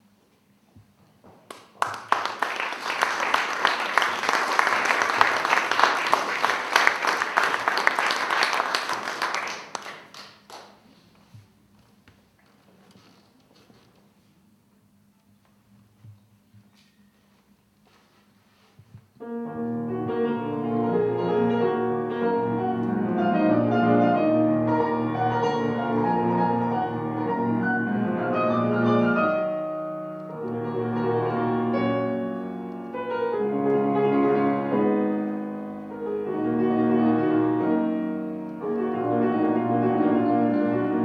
Impromptus es moll DV 946 für Klavier